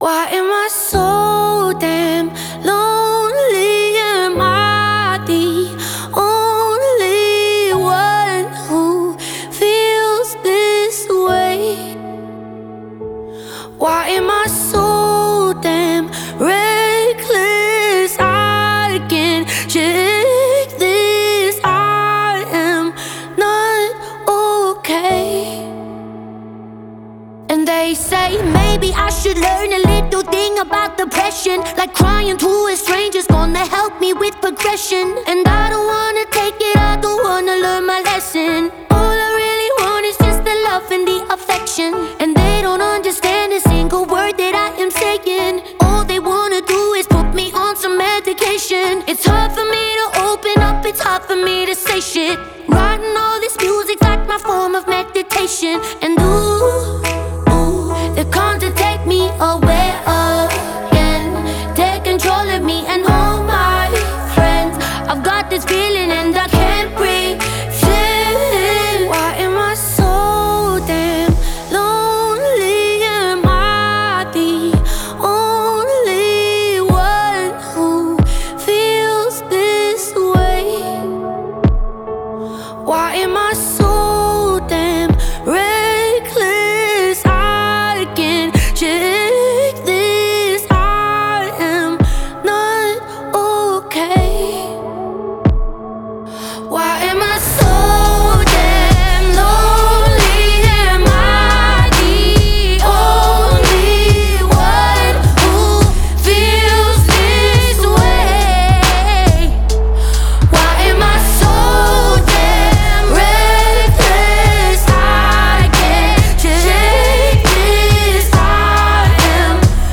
Настроение трека — меланхоличное, но с надеждой на лучшее.